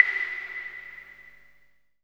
34 V.CLAVE-L.wav